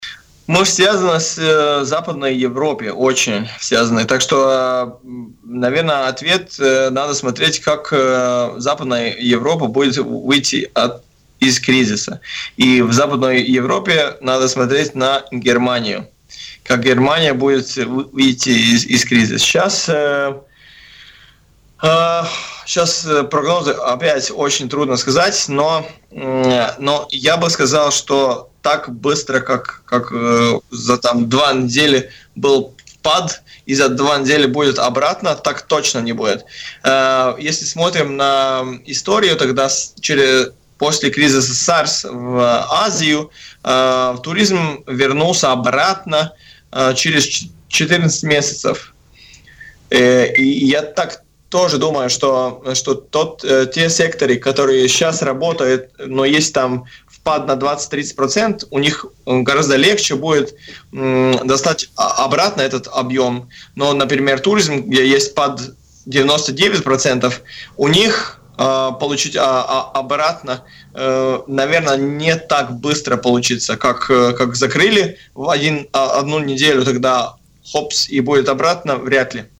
в эфире радио Baltkom